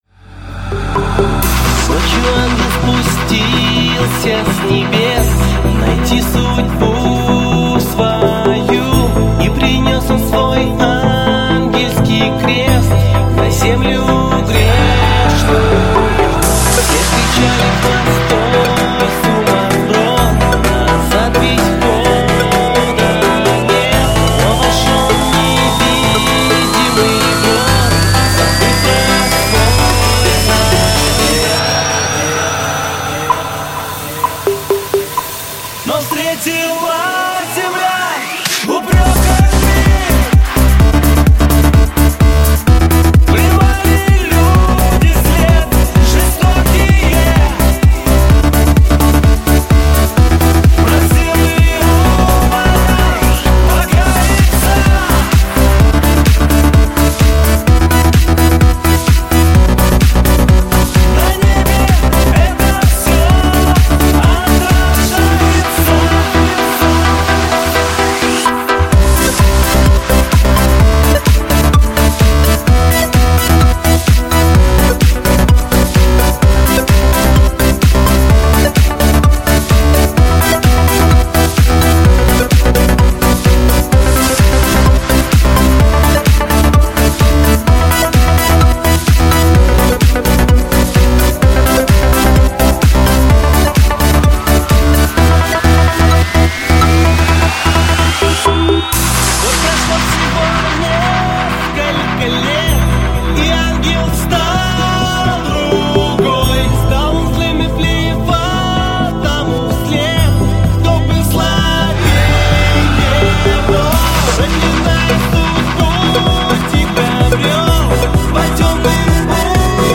Жанр: Electro